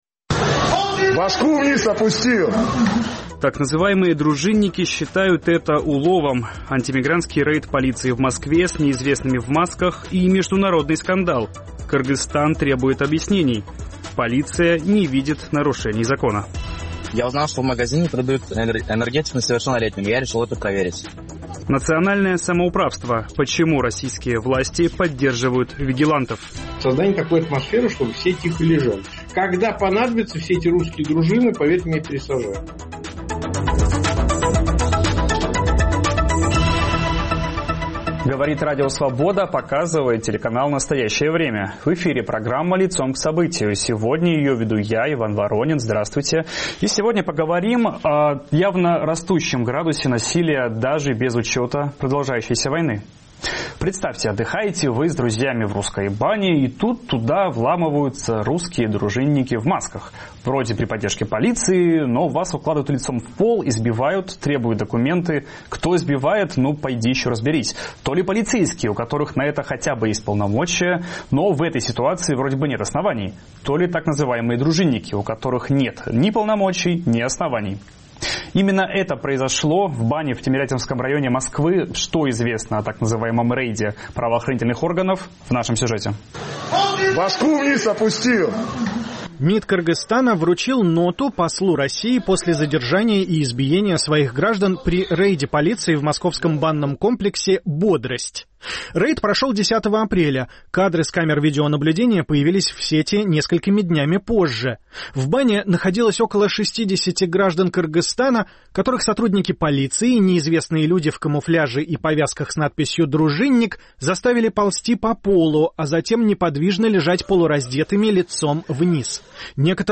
Облава на мигрантов в московской бане: посла РФ вызвали в МИД Кыргызстана. О росте мигрантофобии, которая бьёт по экономике России, говорим с правозащитником